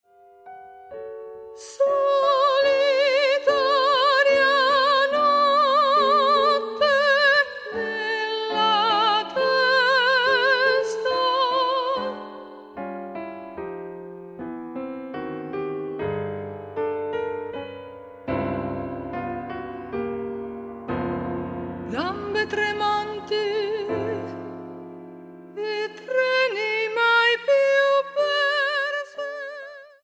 for female voice and two pianists
a Steinway mod.D 274 piano.